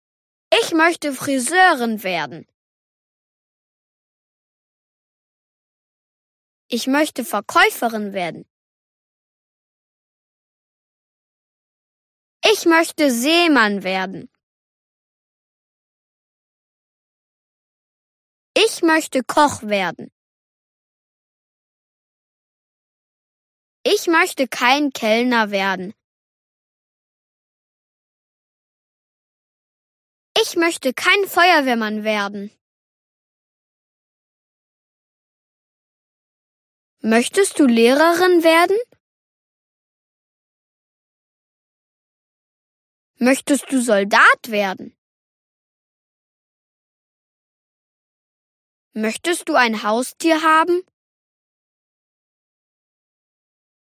Z pomocą kursu audio dzieci mogą słuchać prawidłowej wymowy niemieckich słów oraz dialogów, co rozwija ich umiejętności porozumiewania się w języku niemieckim.